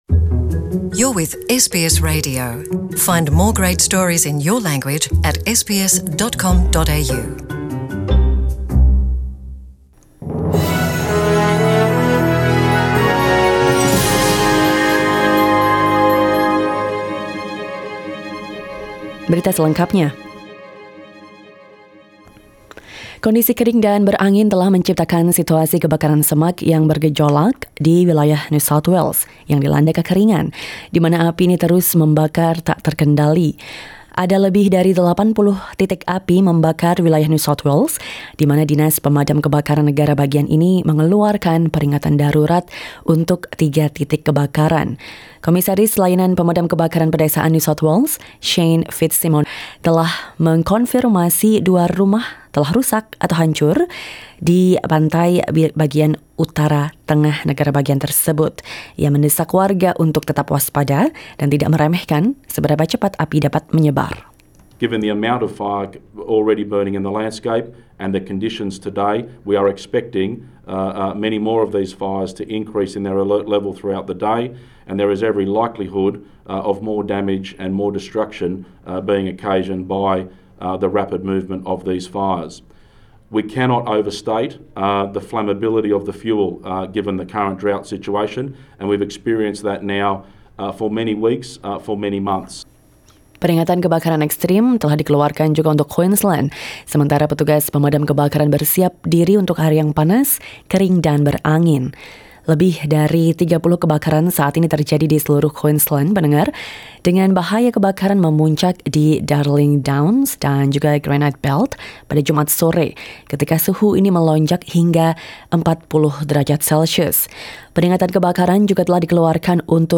SBS Radio news in Indonesian - 8 November 2019